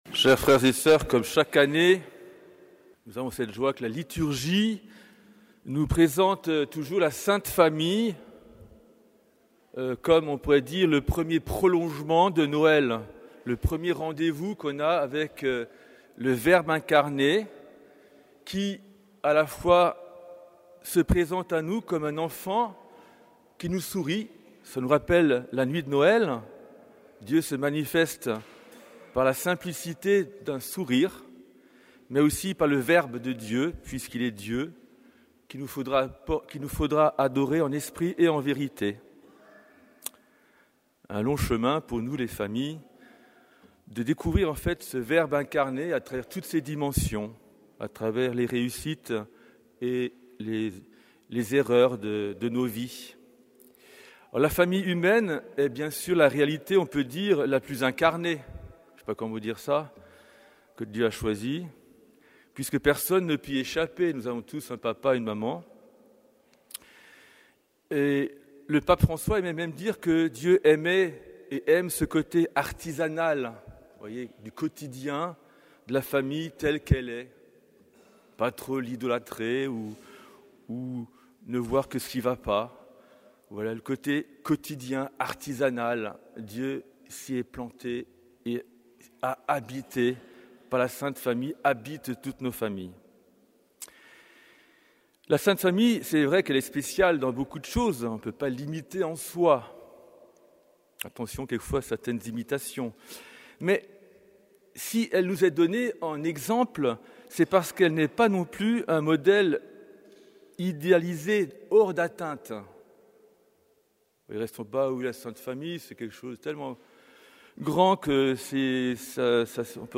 Homélie de la fête de la Sainte Famille de Jésus, Marie et Joseph